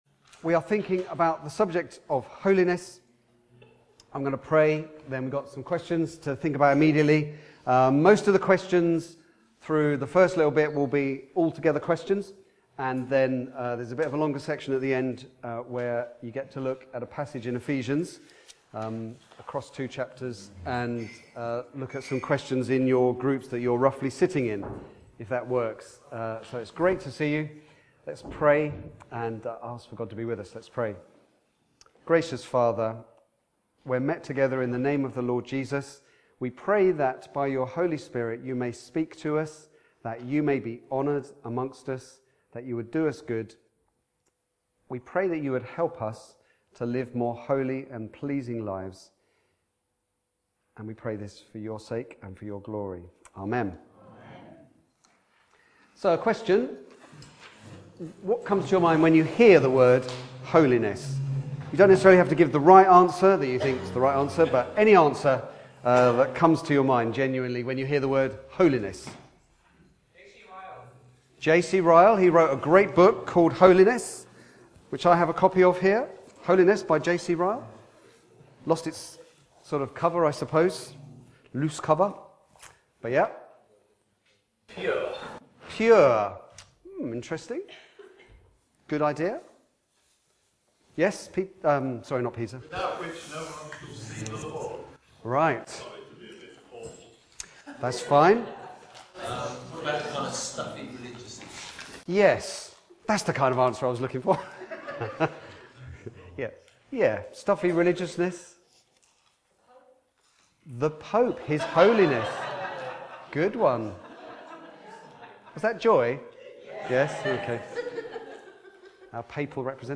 Back to Sermons TalkBack